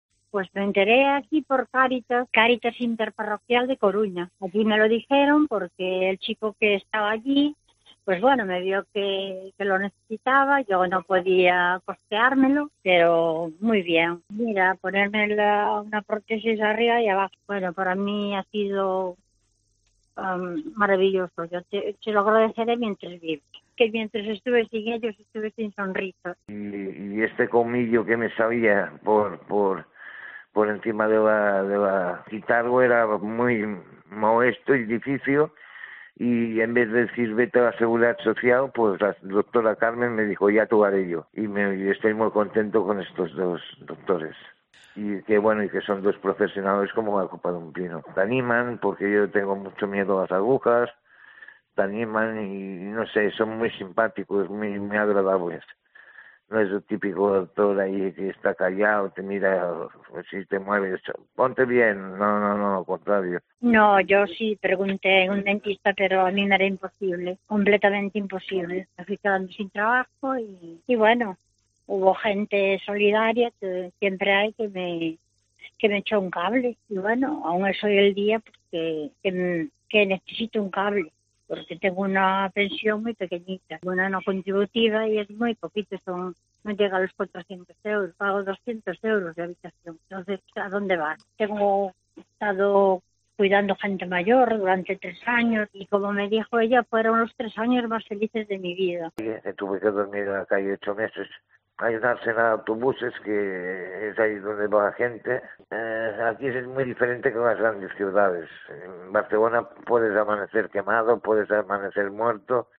Dos usuarios del servicio de odontología solidaria de Cáritas en Santiago nos cuentan cómo les cambió la vida recibir esta ayuda.